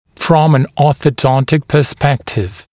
[frɔm ən ˌɔːθə’dɔntɪk pə’spektɪv][фром эн ˌо:сэ’донтик пэ’спэктив]с ортодонтической точки зрения, с точки зрения перспектив ортодонтического лечения